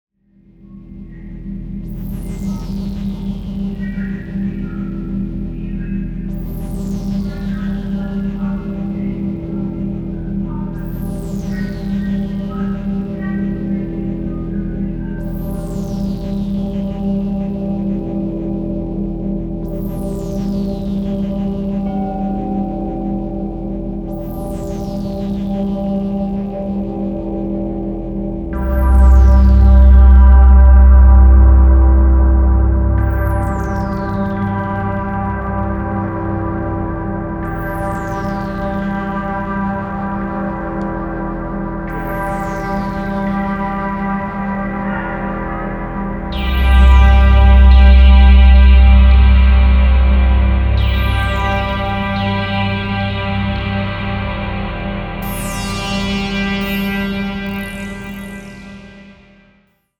すこぶるディープで内省的なトライバル/アンビエント/クロスオーヴァーなグルーヴ。